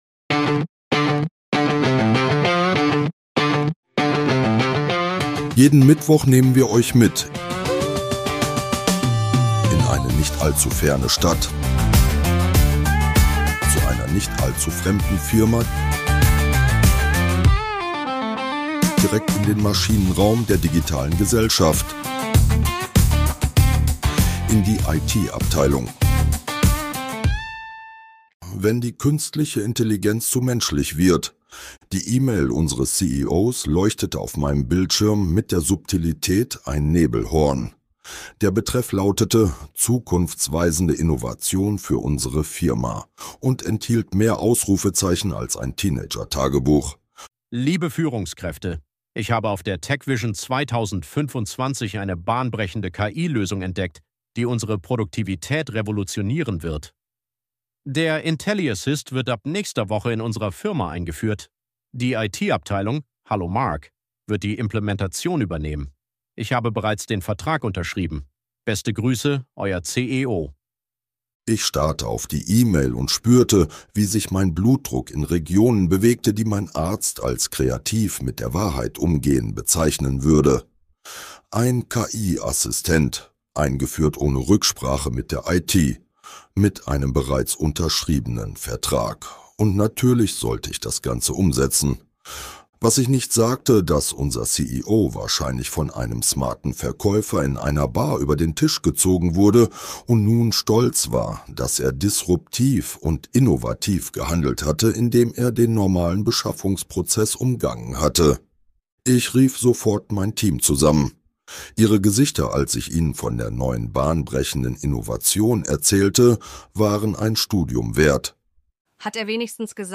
Dieser Podcast ist Comedy.